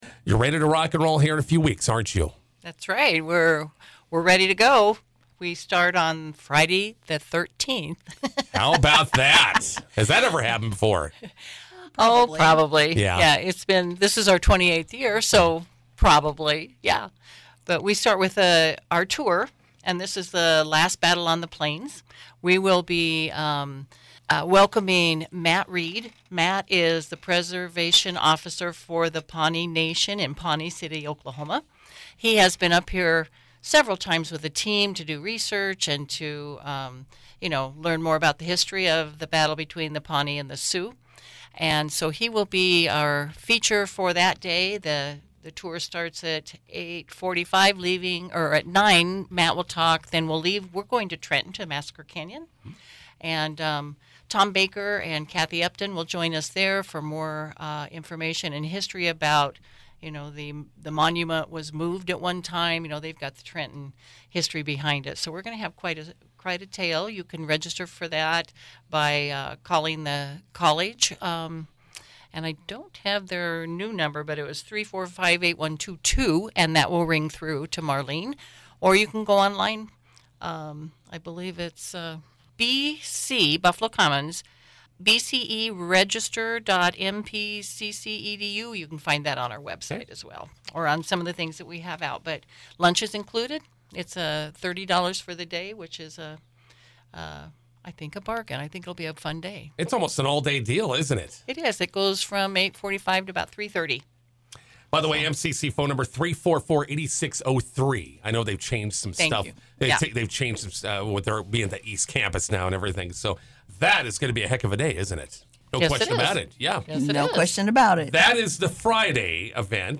INTERVIEW: Buffalo Commons Storytelling Festival is back for it’s 28th year.